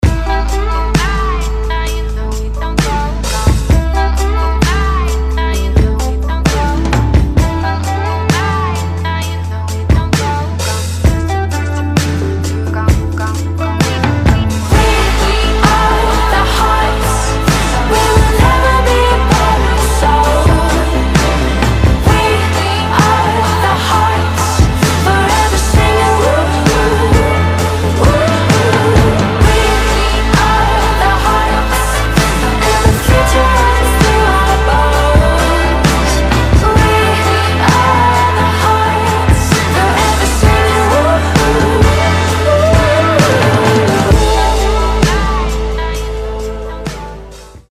• Качество: 320, Stereo
красивые
женский вокал
спокойные
Alternative Rock
indie rock